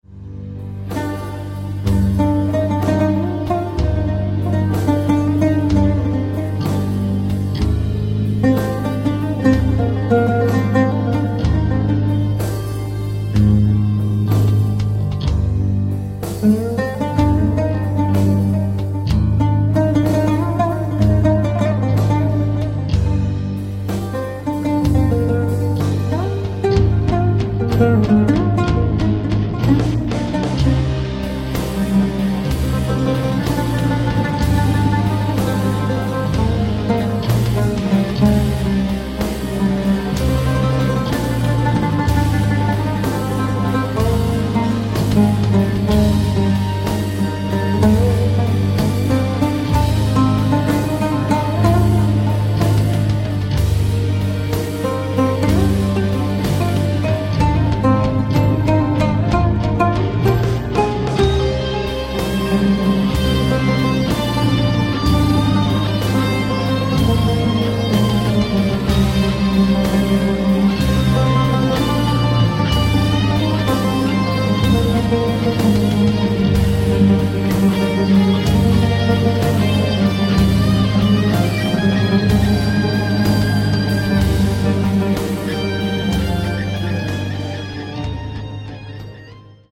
composer, lute & oud player from Japan
Contemporary
Oud , Progressive Metal